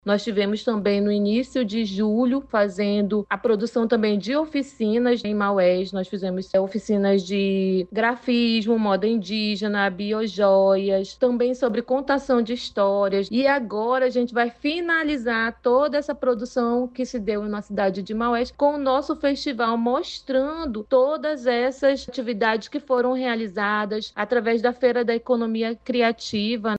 SONORA-2-FESTIVAL-INDIGENA-MAUES-.mp3